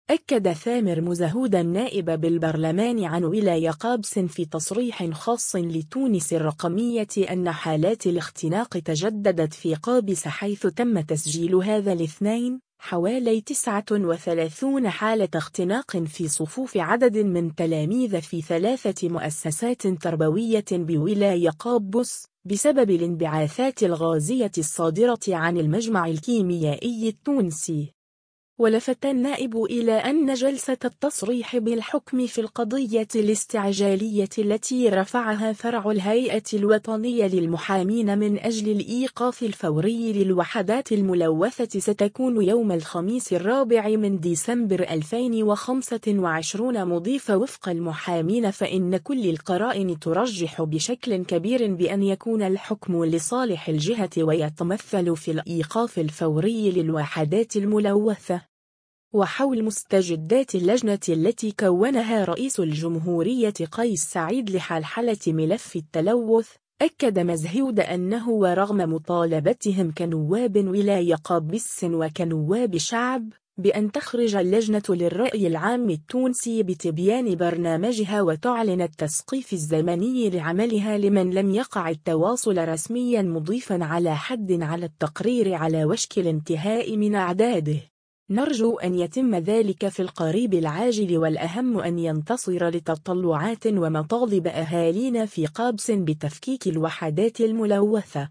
أكد ثامر مزهود النائب بالبرلمان عن ولاية قابس في تصريح خاص لـ”تونس الرقمية” أن حالات الاختناق تجددت في قابس حيث تم تسجيل هذا الاثنين، حوالي 39 حالة اختناق في صفوف عدد من تلاميذ في 3 مؤسسات تربوية بولاية قابس، بسبب الانبعاثات الغازية الصادرة عن المجمع الكيميائي التونسي.